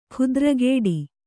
♪ khudragēḍI